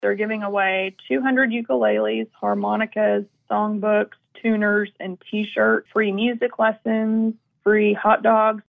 provides commentary.